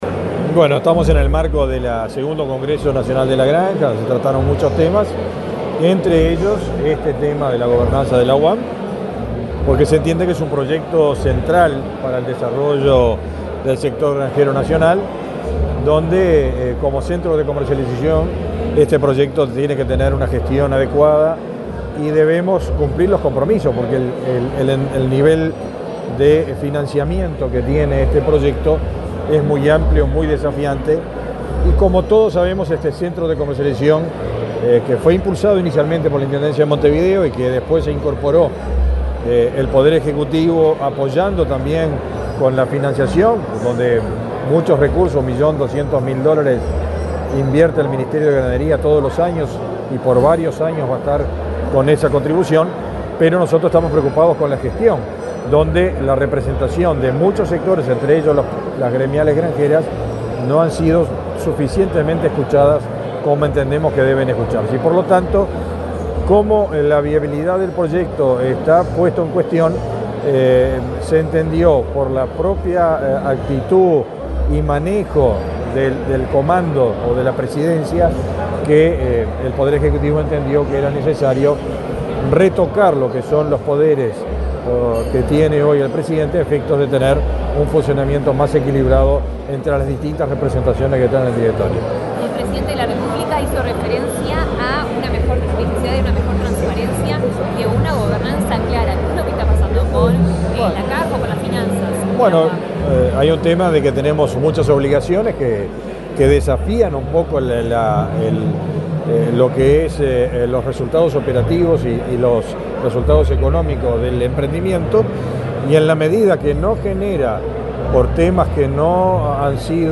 Este miércoles 7, en Mellila, departamento de Montevideo, el ministro de Ganadería, Fernando Mattos, dialogó con la prensa, luego de participar en el